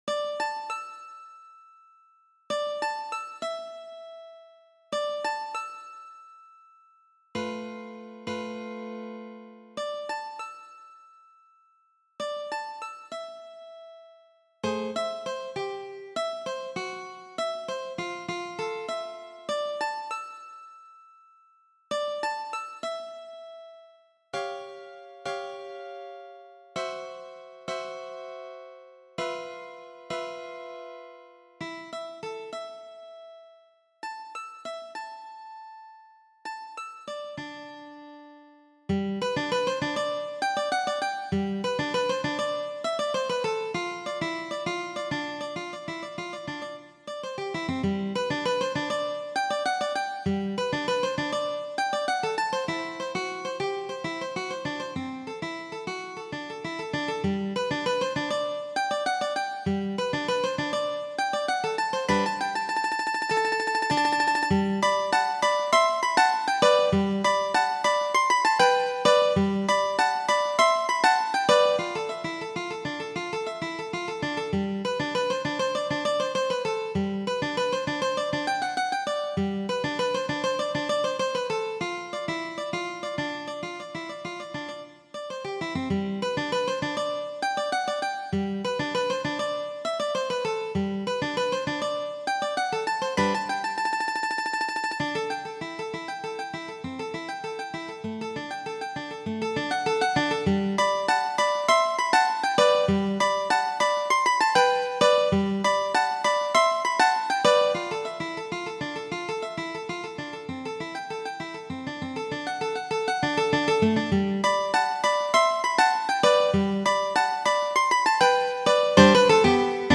It was inspired by the view of the bustling outdoor market place of Groningen, on a rainy day; it is a study in changing meters and left-hand articulations.